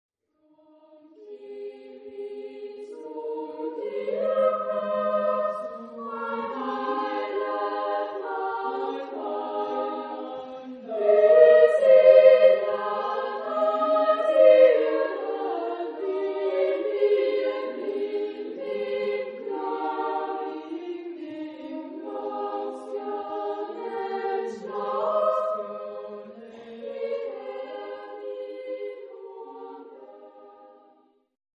Genre-Style-Form: Secular ; Popular
Type of Choir: SSA  (3 children OR women voices )
Tonality: A major
Liturgical Use: Christmas